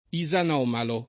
isanomalo [ i @ an 0 malo ]